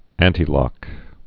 (ăntē-lŏk, ăntī-)